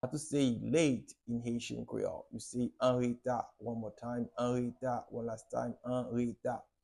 How to say “Late” in Haitian Creole – “Anreta” pronunciation by a native Haitian Teacher
“Anreta” Pronunciation in Haitian Creole by a native Haitian can be heard in the audio here or in the video below:
How-to-say-Late-in-Haitian-Creole-Anreta-pronunciation-by-a-native-Haitian-Teacher-1.mp3